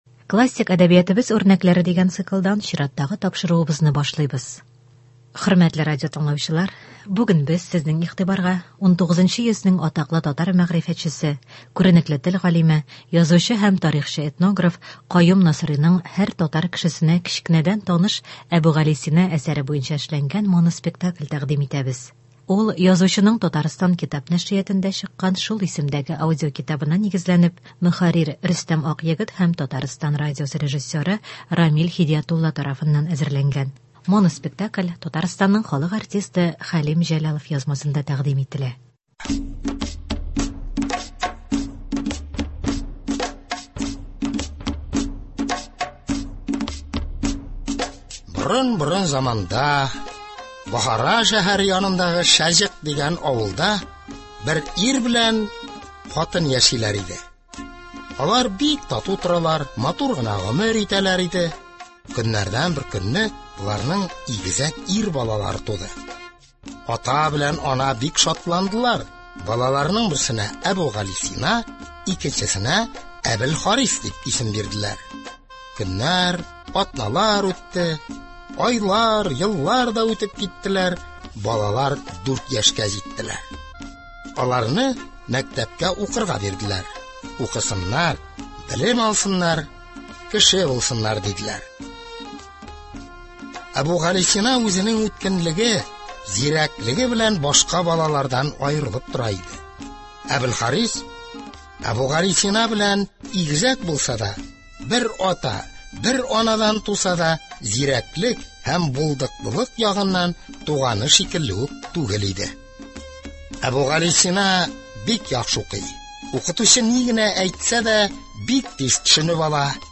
Моноспектакль.